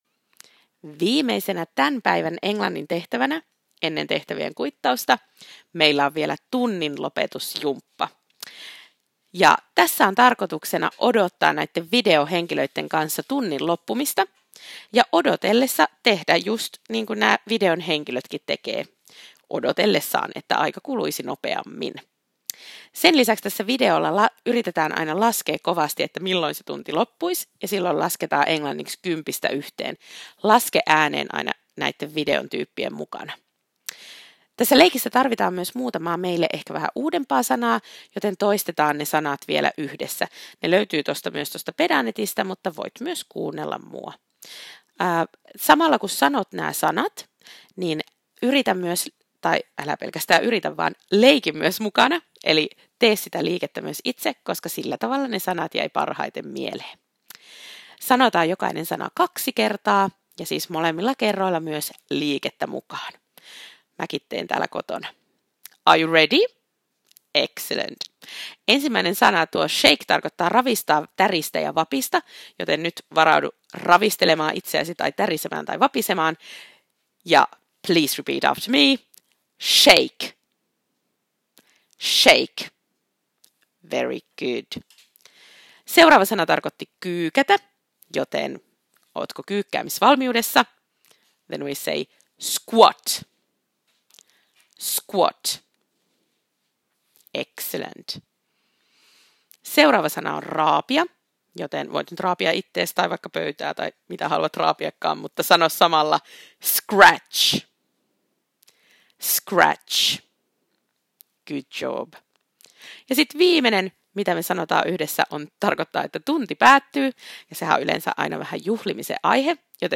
* Toista ennen leikkiä vielä nämä sanat opettajan kanssa. Open jutut kuulet, kun painat sanojen alla olevaa play -nuolta.